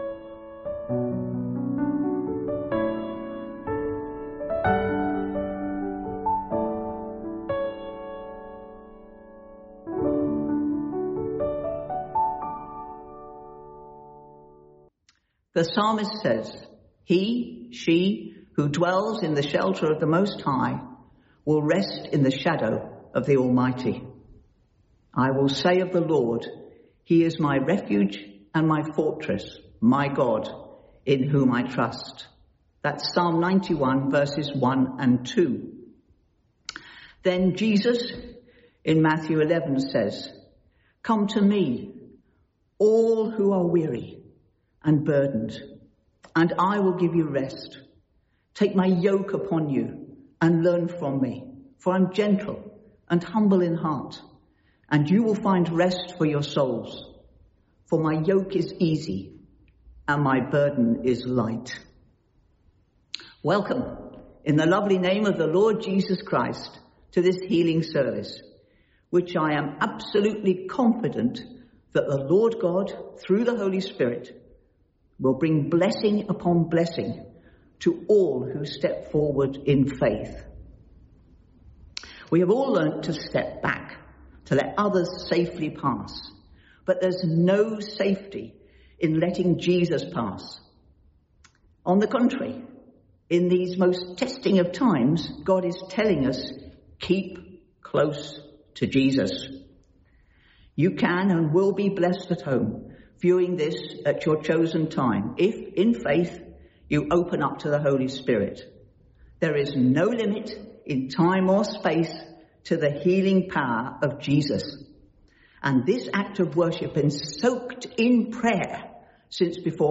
From Service: "10.30am Service"